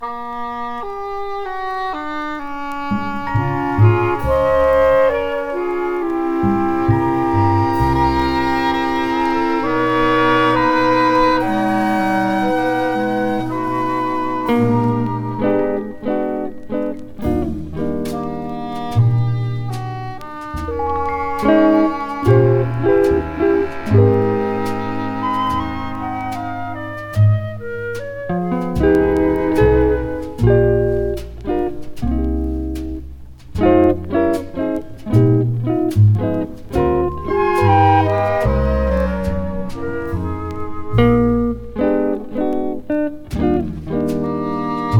Jazz, Cool Jazz　Japan　12inchレコード　33rpm　Mono